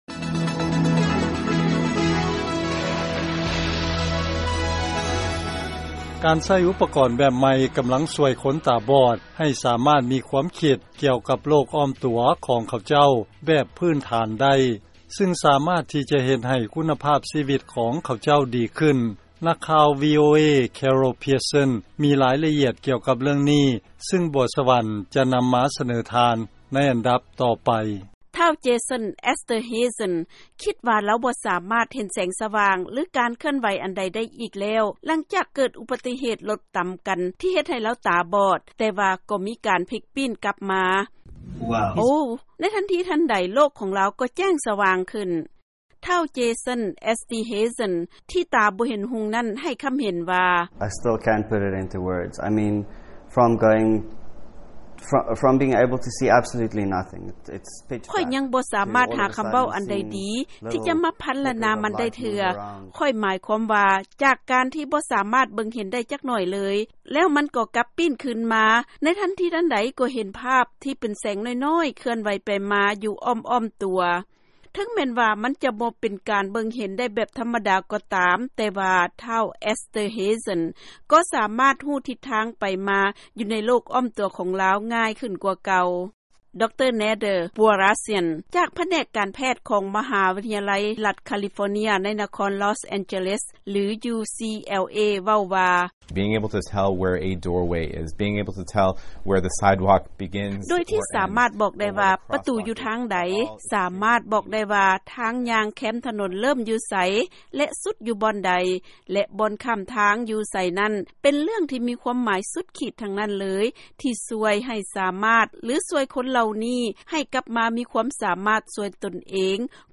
ເຊີນຟັງລາຍງານກ່ຽວກັບການໃຊ້ອຸປະກອນຝັງໃສ່ສະໝອງ ສາມາດເຮັດໃຫ້ຄົນຕາບອດເຫັນຮຸ່ງໄດ້